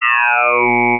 Ow!
The next sound is Ow!, and was made by taking Ooh-Ahh from the previous example, taking off the constant Ahh, and playing it backwards:
ow.wav